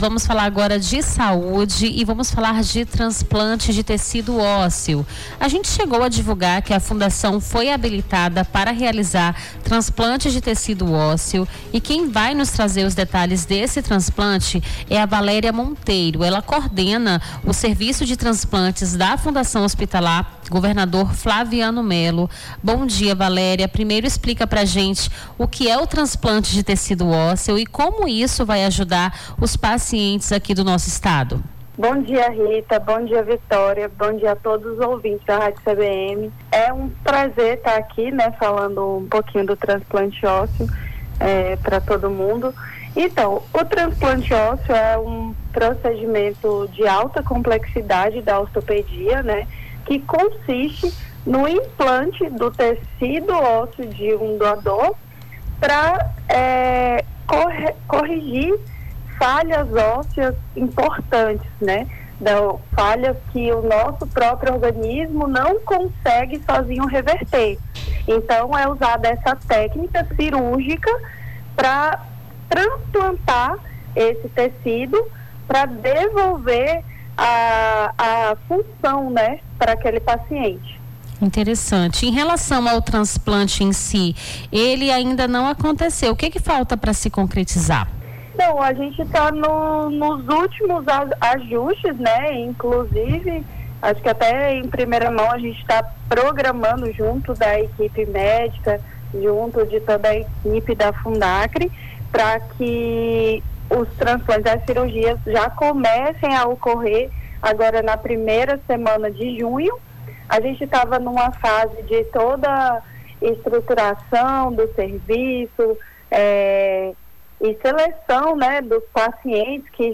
Nome do Artista - CENSURA - ENTREVISTA TRANSPLANTE ÓSSEO (23-05-25).mp3